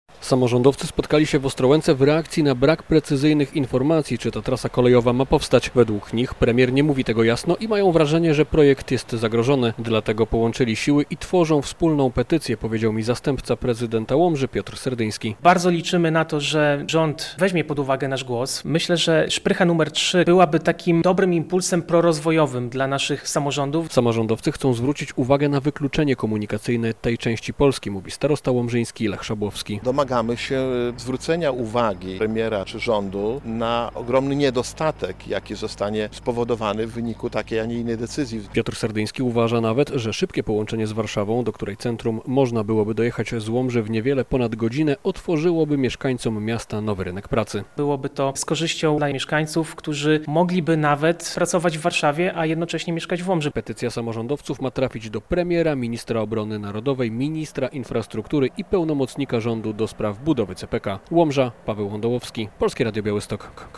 relacja
Bardzo liczymy na to, że rząd weźmie pod uwagę nasz głos. Myślę, że "szprycha" numer 3 byłaby dobrym impulsem prorozwojowym dla naszych samorządów - powiedział Polskiemu Radiu Białystok zastępca prezydenta Łomży Piotr Serdyński.